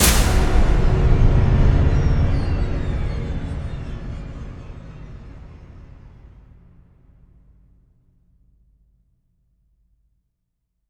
LC IMP SLAM 8A.WAV